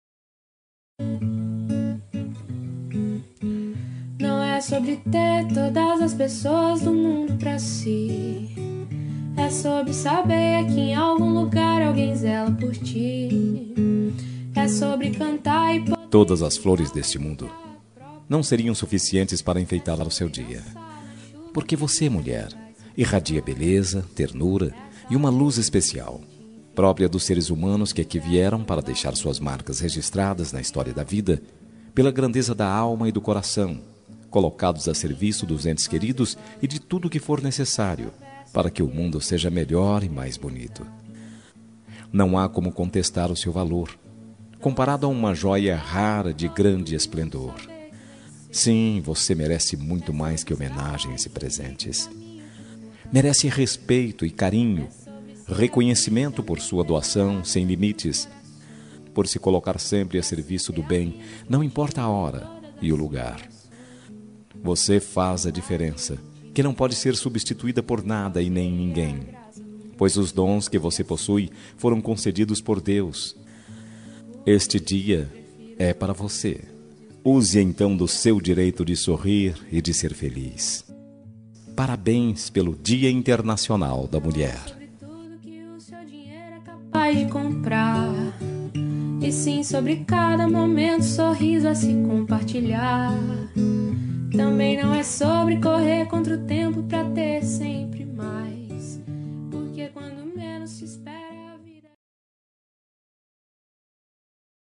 Dia das Mulheres Neutra – Voz Masculina – Cód: 5271